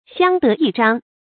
xiāng dé yì zhāng
相得益彰发音
成语正音 相，不能读作“xiànɡ”。